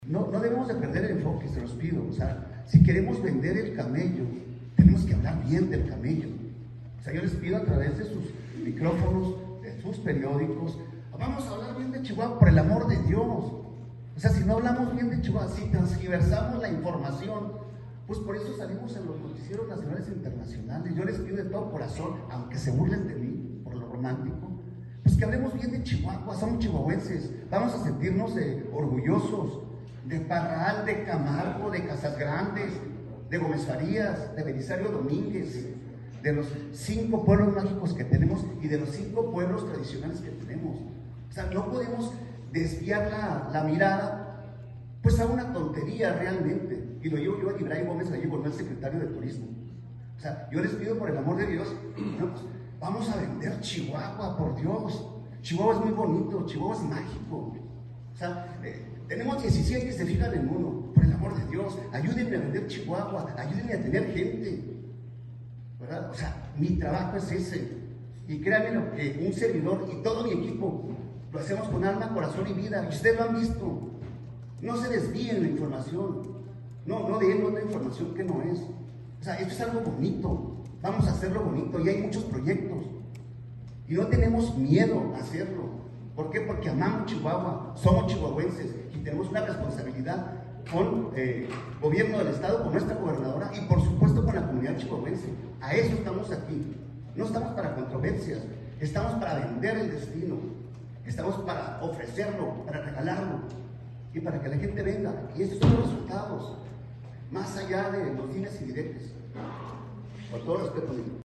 AUDIO: EDIBRAY GÓMEZ, TITULAR DE LA SECRETARÍA DE TURISMO DEL ESTADO
El funcionario, aseguró desconocer la procedencia y el autor de la famosa escalera -hoy conocida como narco escalera-, y exigió a los medios de comunicación a no perder el enfoque , no tergiversar la información y generar polémicas . Finalmente, el secretario, indicó que él y su equipo de trabajo está enfocado en promocionar a la entidad, y no va a desviar en distracciones, golpeó la mesa desde donde ofreció una rueda de prensa exigiendo respeto al trabajo de la dependencia a su cargo.